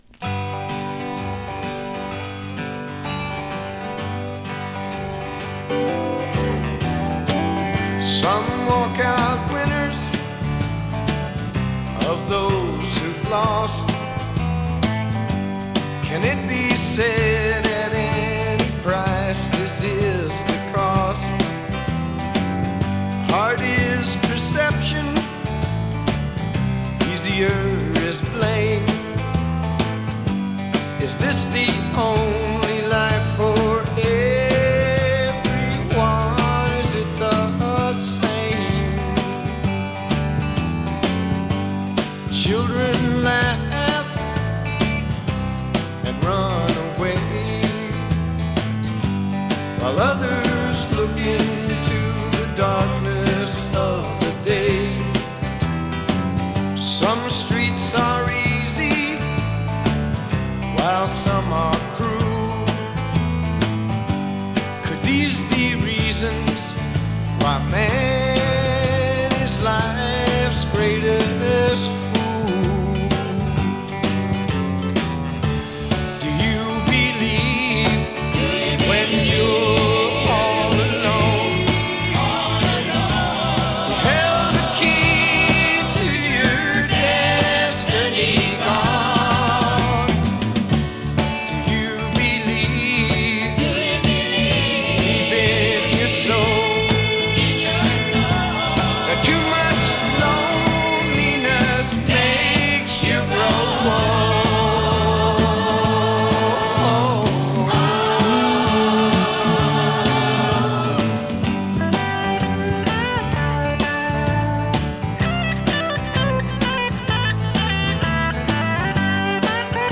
アシッド カントリー フォーク ファンク